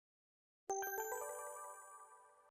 KFfakOnuPwG_Love-alarm-2.0-Tono-de-notificacion.mp3